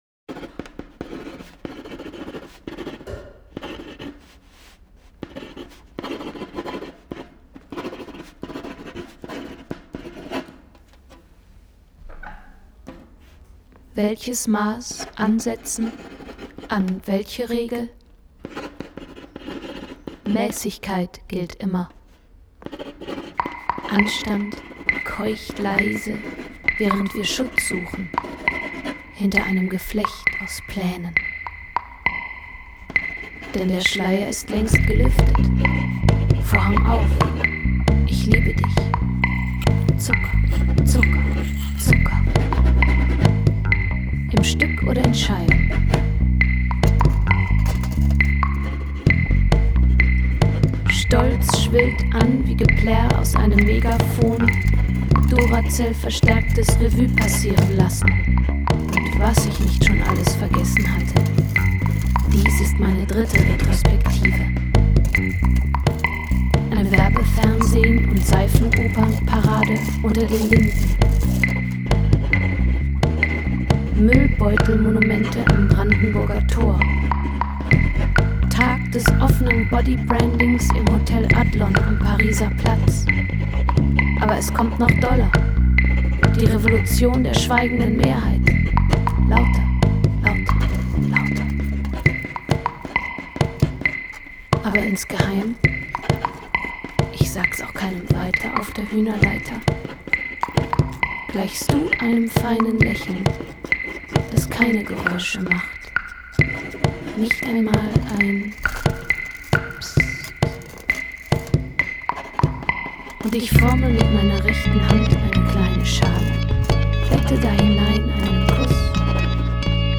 und eines der Mini-Hörspiele unter den Gesprochenen Popsongs